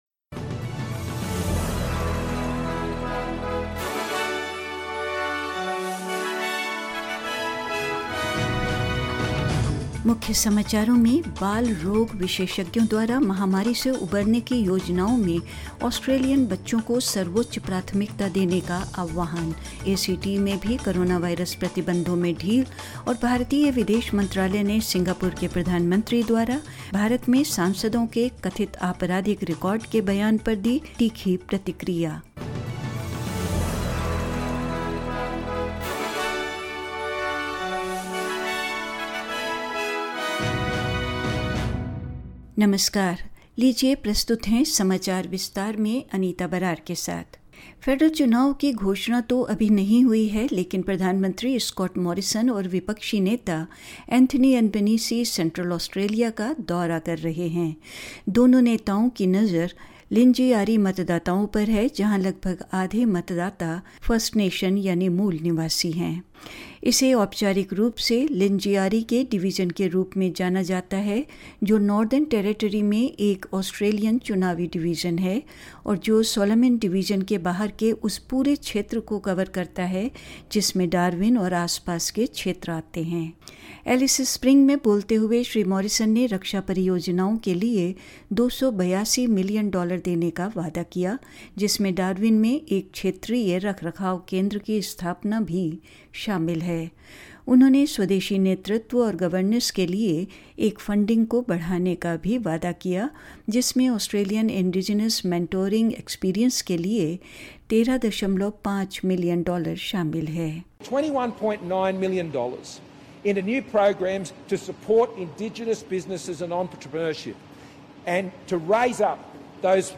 In this latest SBS Hindi bulletin: Paediatricians call for Australian children to be a top priority in the country's pandemic recovery plans; The A-C-T joins New South Wales and Victoria in winding back coronavirus restrictions; India expresses displeasure over Singapore PM’s remarks on criminal records of Indian MPs and more news ...